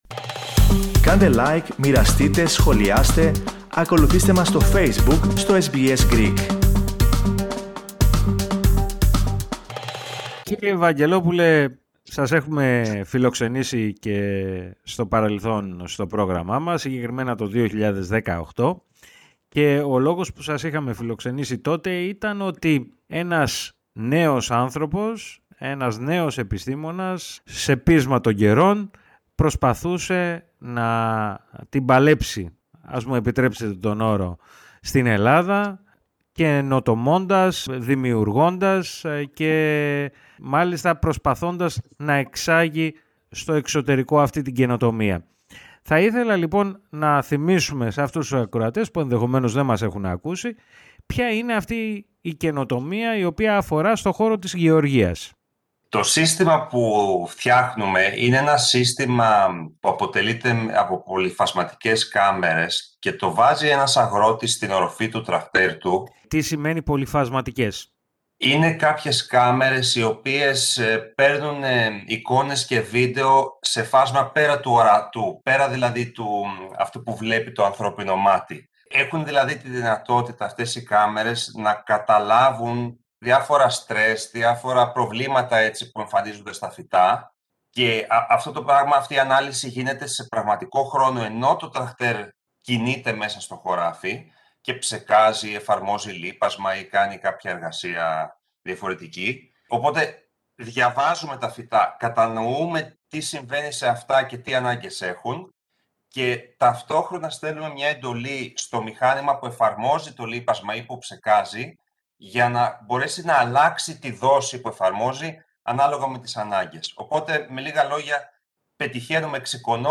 μίλησε στο Ελληνικό Πρόγραμμα της ραδιοφωνίας SBS.